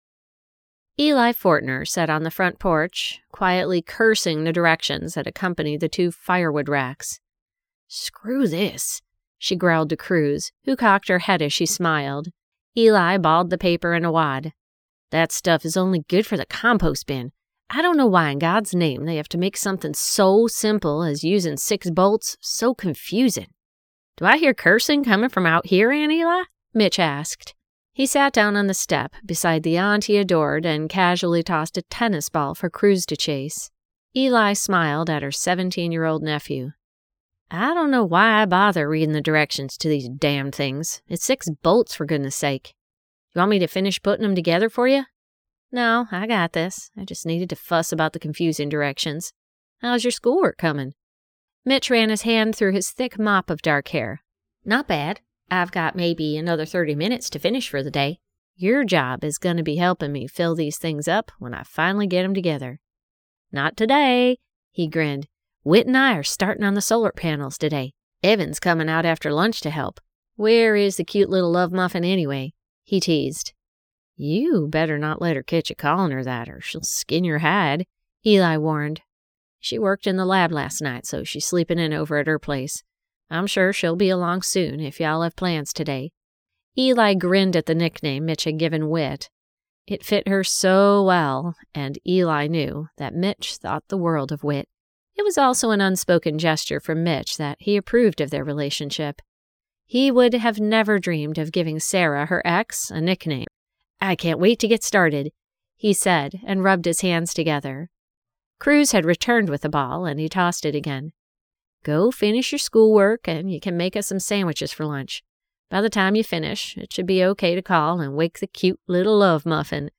The Star Child by Ali Spooner [Audiobook]